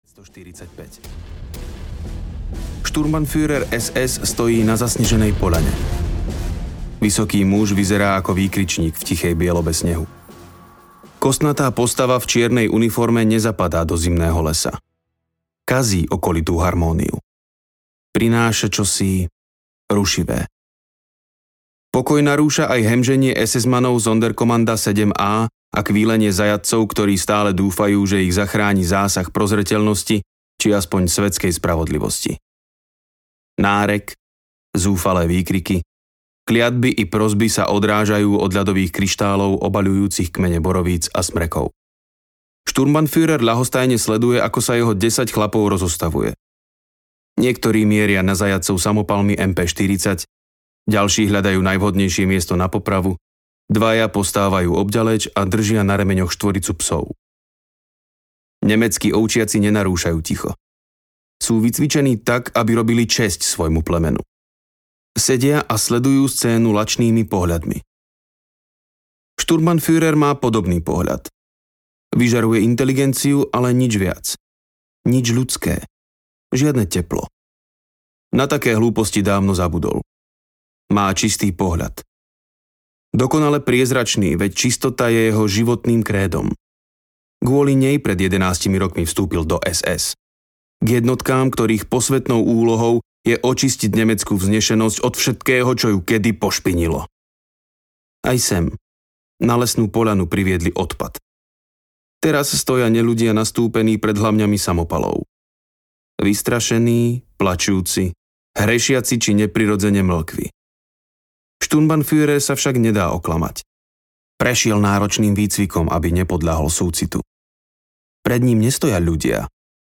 Na smrť I + II audiokniha
Ukázka z knihy
na-smrt-i-ii-audiokniha